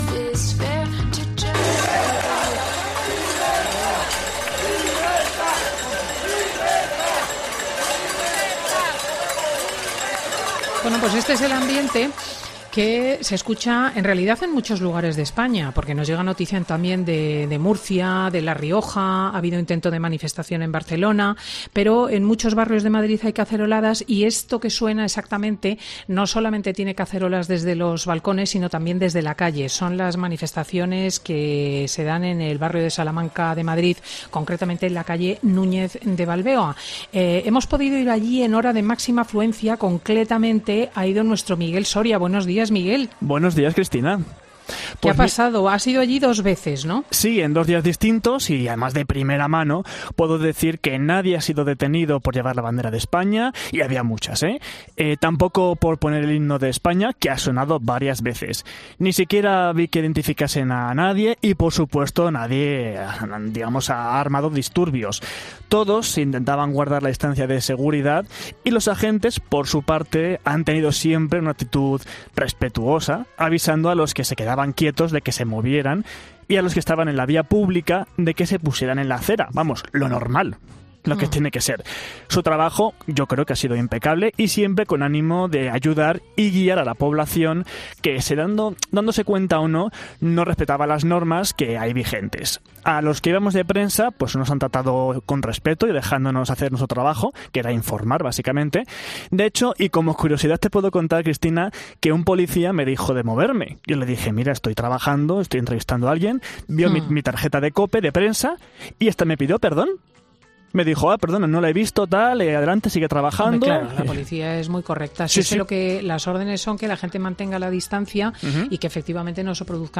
En Fin de Semana asistimos a la protesta del Barrio Salamanca para escuchar las protestas de los vecinos por las decisiones del Gobierno sobre el...
No son aún las 21h pero empiezan a sonar cacerolas de protesta, tanto desde balcones como en la calle .
Y lo hacen con toda amabilidad y respeto pero con autoridad , que no son incompatibles. 00:00 Volumen Comparte en: Copiar enlace Descargar AGILE_TITLE AGILE_CREDITS Entonces llega un hombre con mascarilla y megáfono .